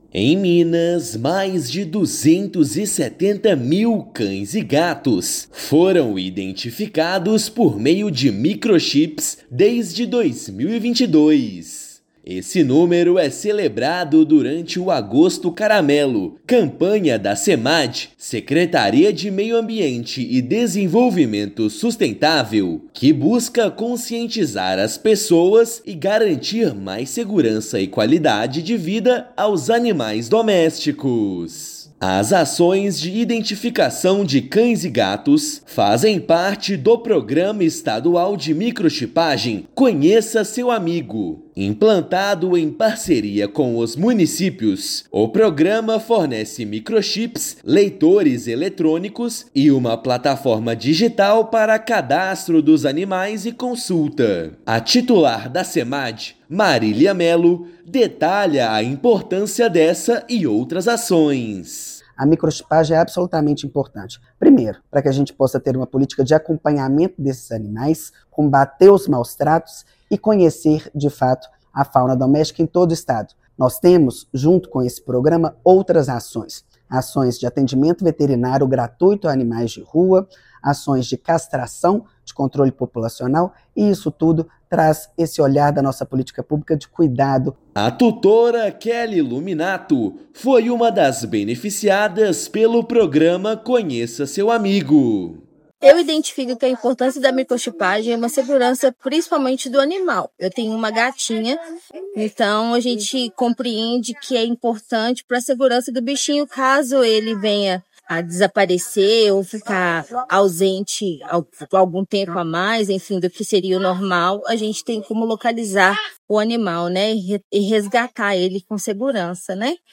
Microchipagem reúne dados, facilita reencontros e fortalece políticas públicas de proteção animal. Ouça matéria de rádio.